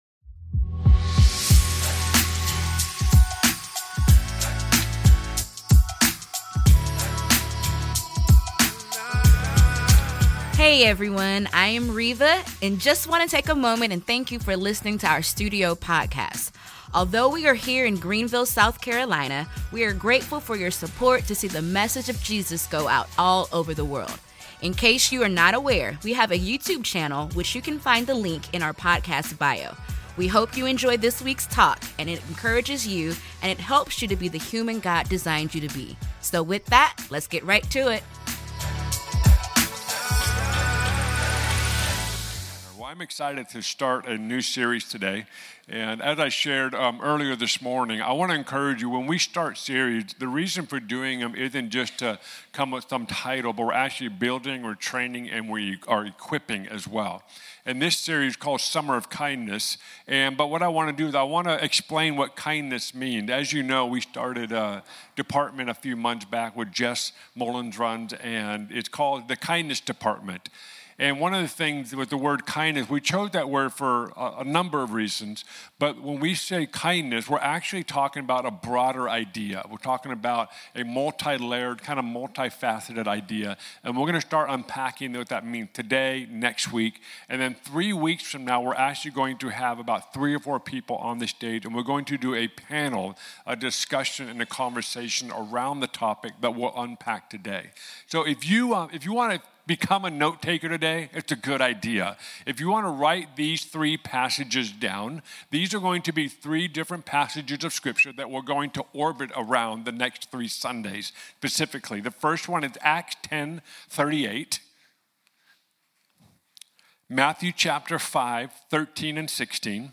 This past Sunday